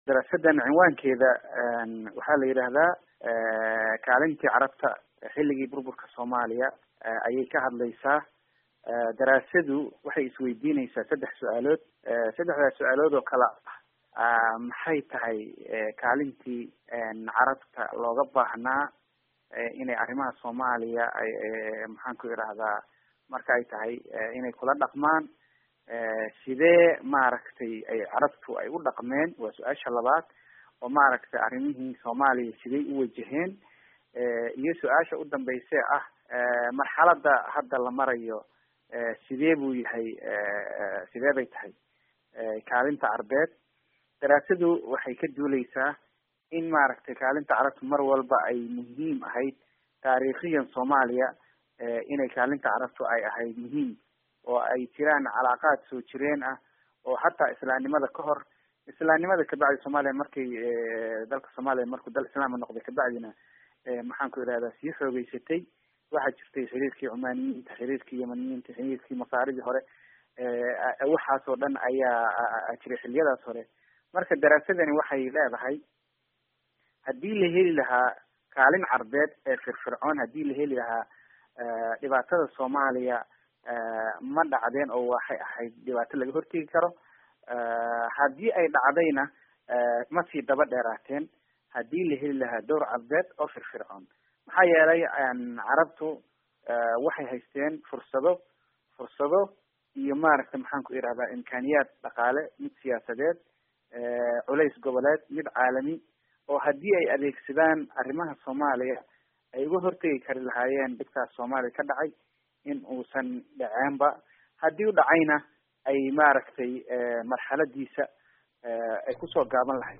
Haddaba, jawaabihii kasoo baxay saddexdaad su’aal kadib cilmi-baaristii la sameeyey, ayaa wariyaheena